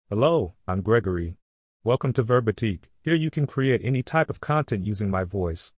GregoryMale US English AI voice
Gregory is a male AI voice for US English.
Voice sample
Listen to Gregory's male US English voice.
Male
Gregory delivers clear pronunciation with authentic US English intonation, making your content sound professionally produced.